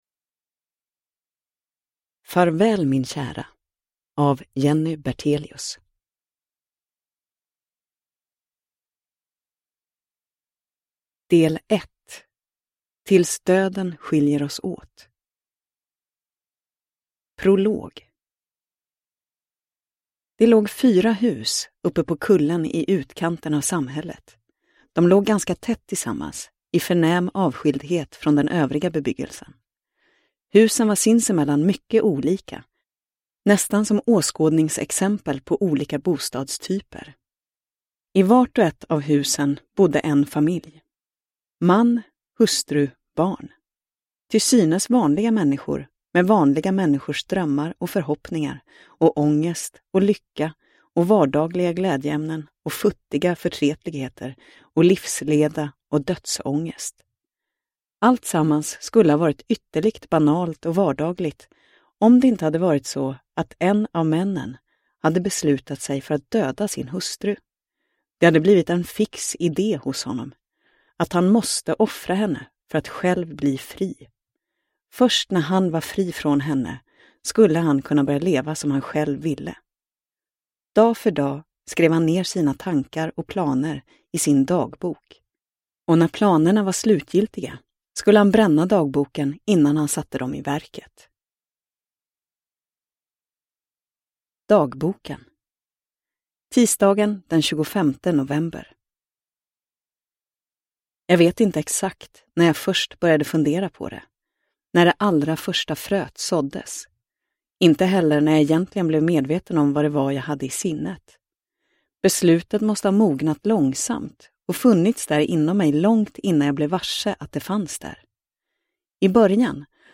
Farväl min kära – Ljudbok – Laddas ner